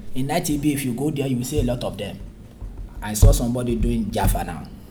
S1 = Bruneian female S3 = Nigerian male